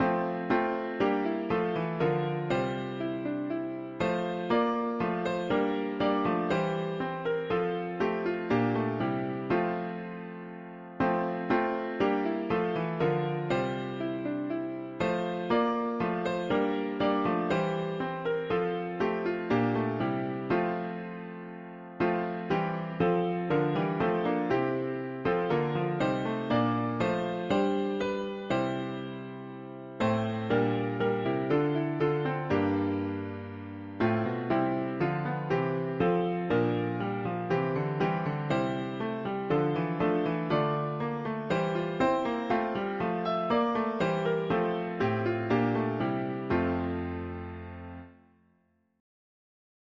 Dutch traditional
Key: F major